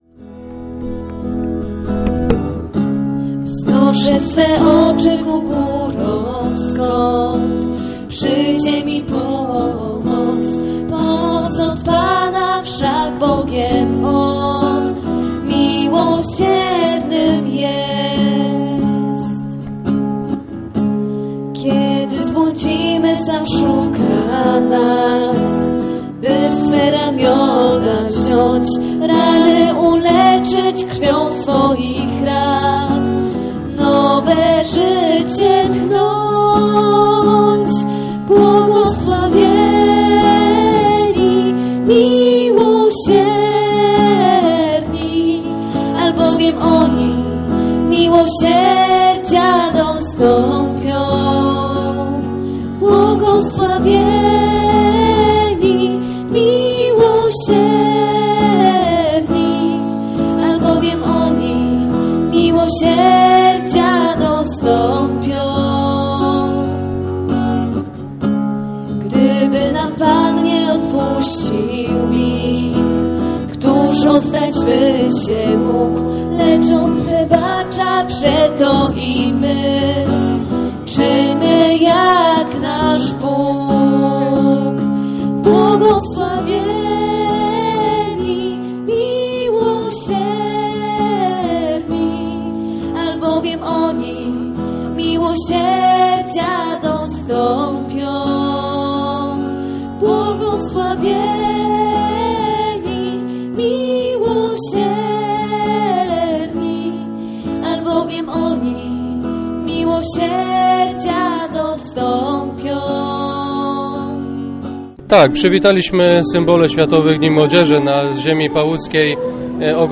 relacja_symbole_wiatowych_dni_mlodziezy_001.mp3